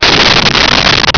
Sfx Crash Firey B
sfx_crash_firey_b.wav